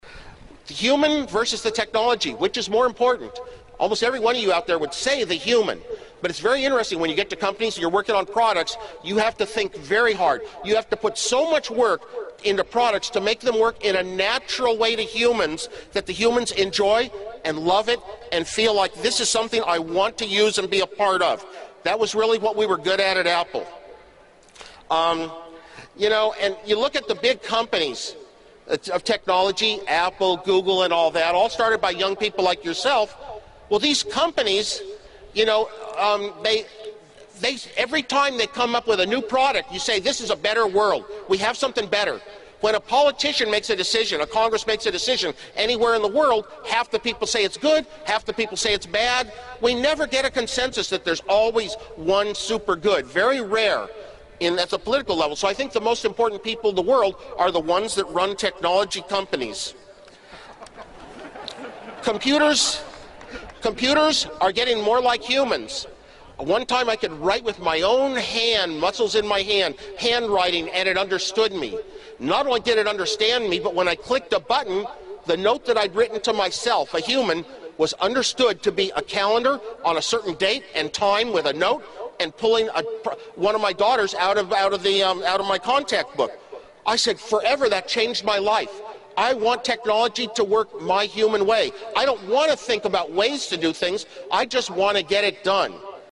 公众人物毕业演讲 第150期:史蒂夫·沃兹尼亚克于加州大学伯克利分校(7) 听力文件下载—在线英语听力室